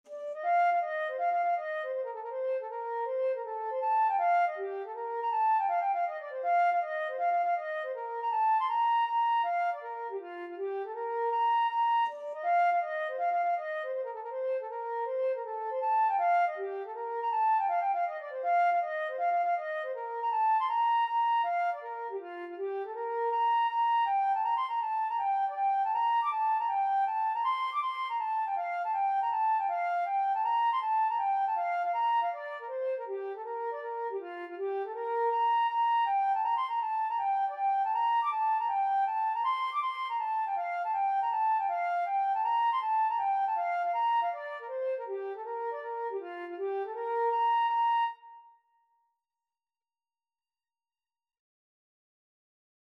F5-D7
2/2 (View more 2/2 Music)
Flute  (View more Easy Flute Music)